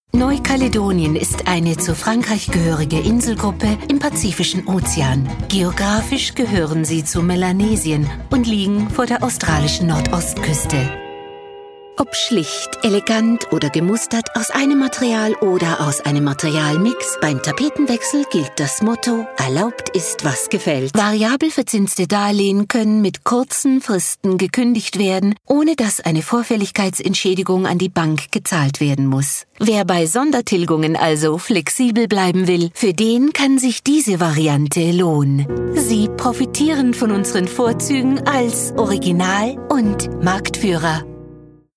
Almanca Seslendirme
Kadın Ses